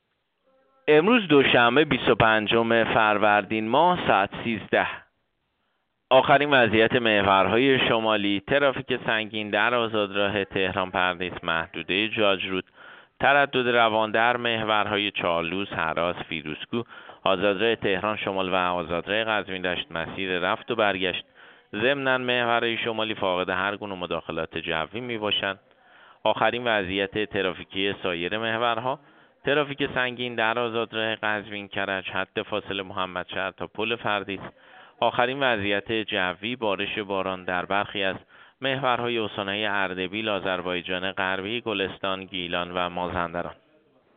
گزارش رادیو اینترنتی از آخرین وضعیت ترافیکی جاده‌ها ساعت ۱۳ بیست و پنجم فروردین؛